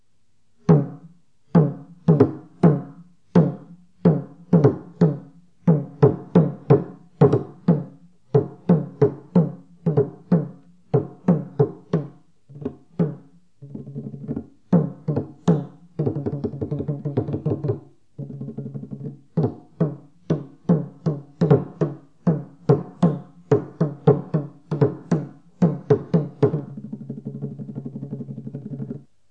4.1.2.2. TRỐNG CƠM